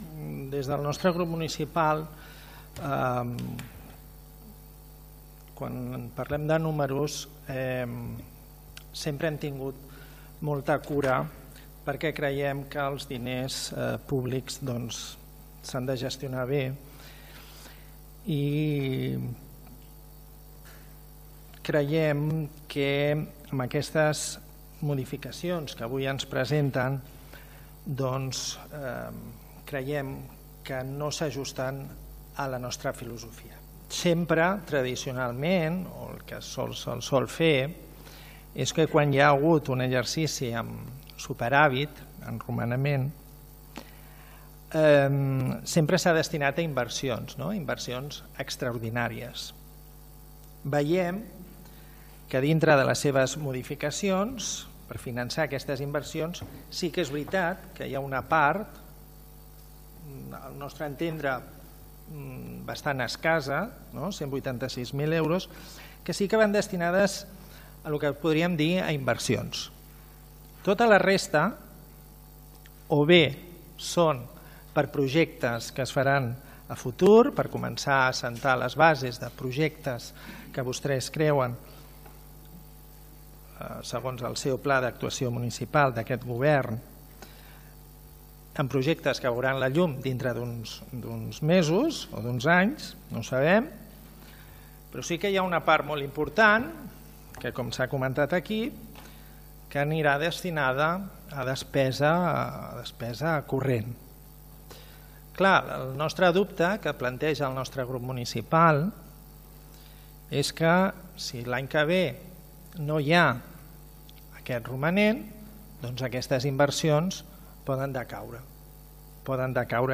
El ple d’aquest dimarts 1 de juliol ha aprovat el destí del superàvit del 2024.
“La gestió de l’àrea d’Hisenda hauria de ser més acurada”, va afegir: